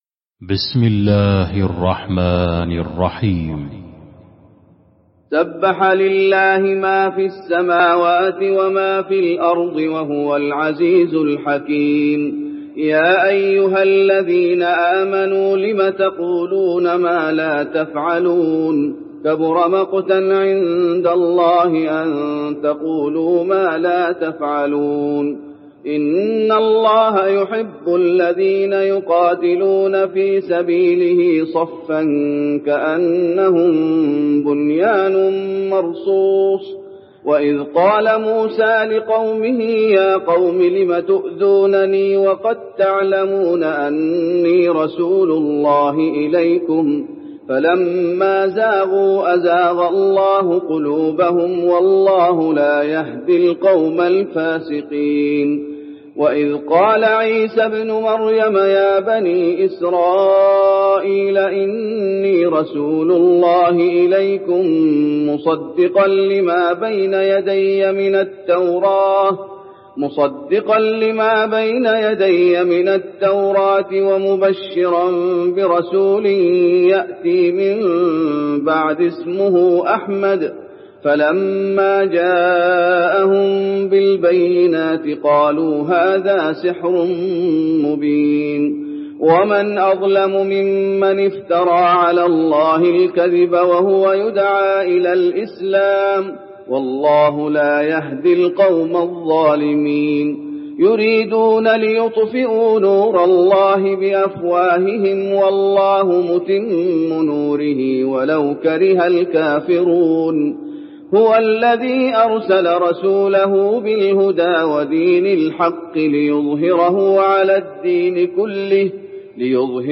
المكان: المسجد النبوي الصف